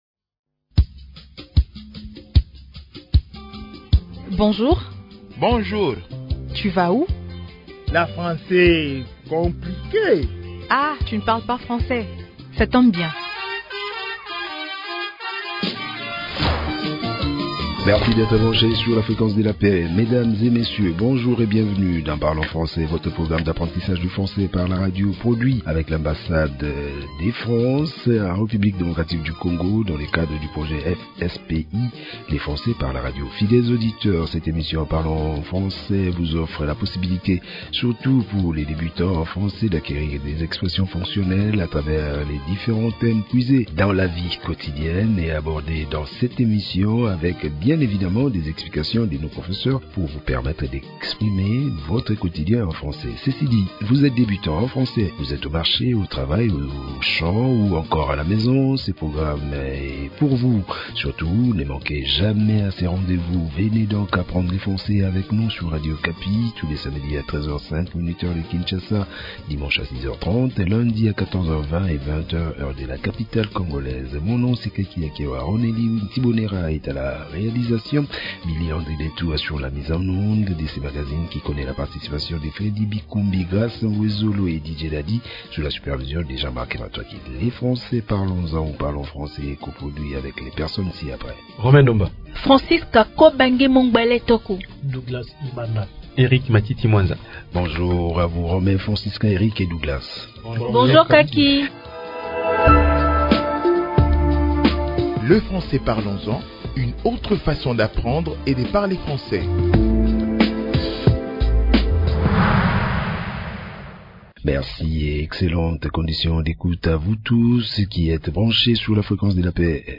Comme nous l'avons remarqué, dans cette nouvelle leçon, nous apprenons à exprimer la fatigue en français. Cet épisode, mettant un accent sur les quatre langues nationales, offre des astuces pour apprendre facilement la langue française.